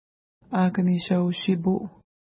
Akaneshau-shipu Next name Previous name Image Not Available ID: 354 Longitude: -58.8649 Latitude: 53.8901 Pronunciation: a:kəneʃa:w-ʃi:pu: Translation: English River Official Name: English River Feature: river